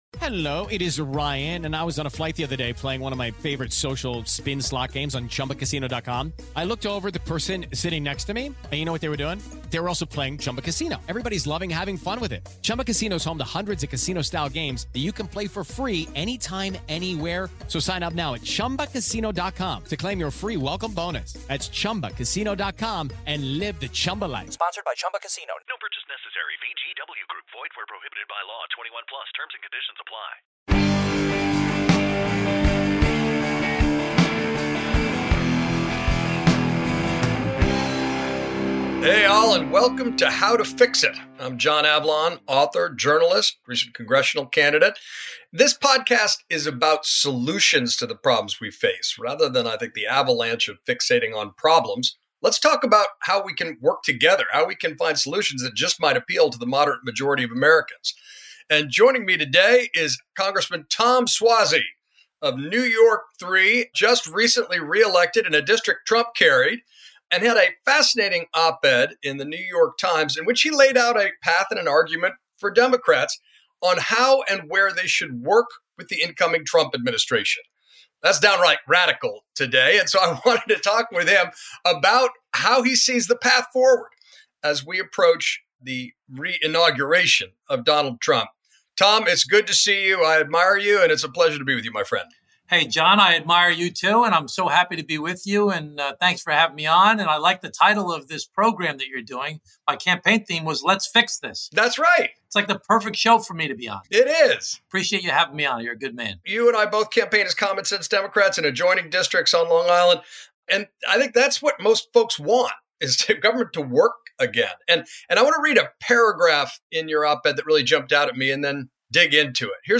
Rep. Tom Suozzi joins John Avlon.